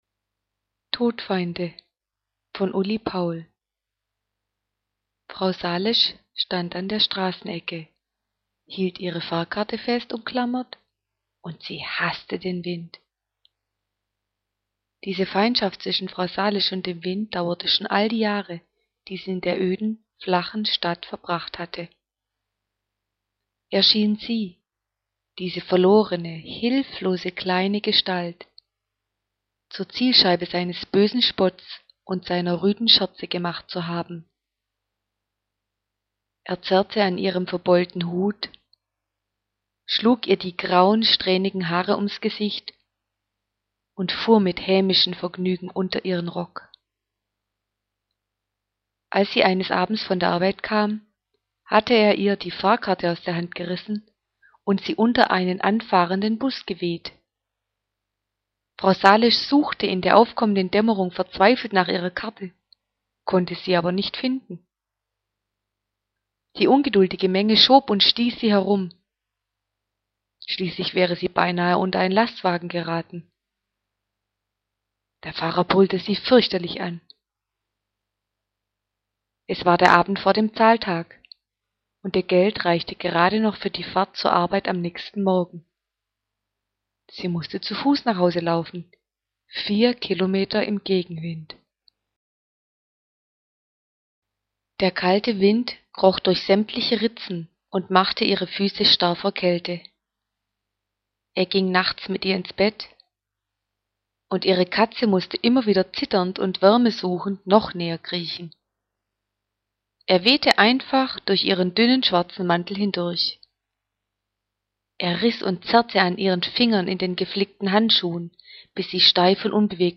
Kostenlose Hörprobe: Eine kleine traurige schwäbische Geschichte Todfeinde: 5 der be eschichten auf eheibe. 80 M inuten voller Spannung und schwarzem Humor.